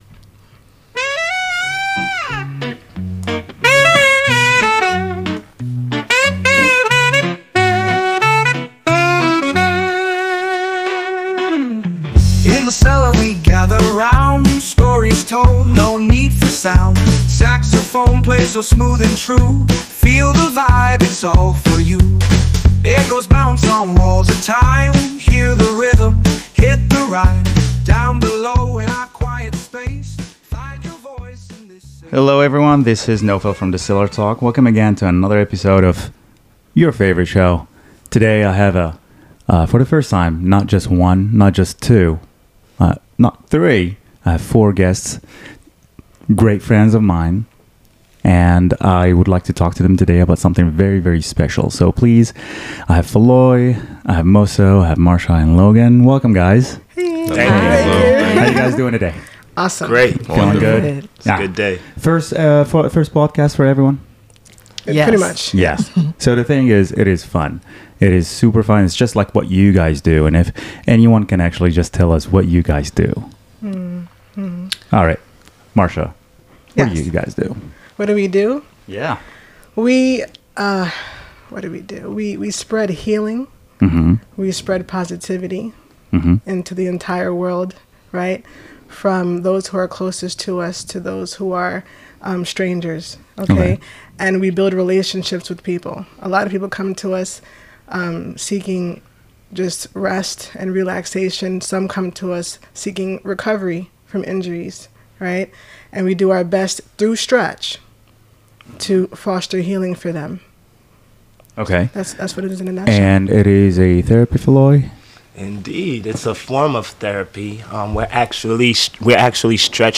I sit down with FOUR certified stretch therapists from Worldwide Stretch Therapy to explore the world of personalized stretching. We get into the benefits of stretch therapy for reducing tension, increasing flexibility, and improving overall well-being. Our guests share their journey into the field, discuss how stretch therapy can address common physical issues like joint pain and muscle tightness, and explain the unique combination of fitness, nutrition, and stretching that their practice offers.